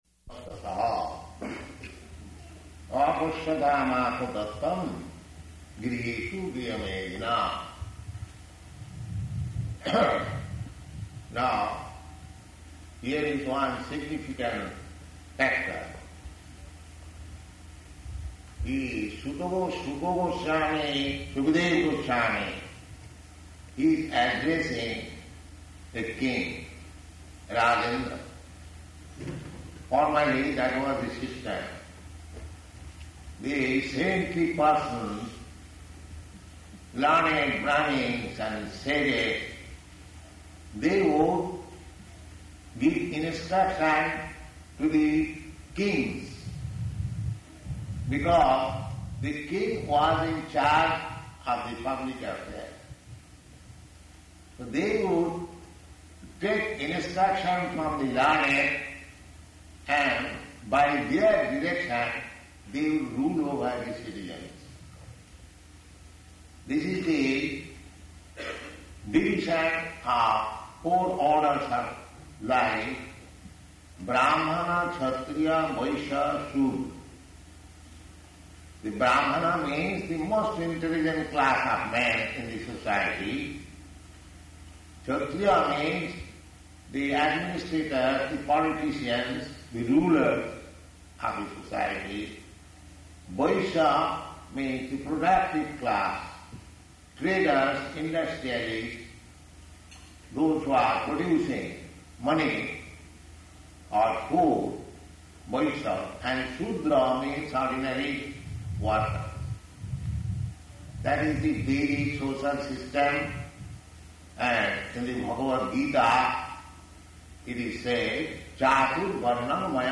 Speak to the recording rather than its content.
Location: Mombasa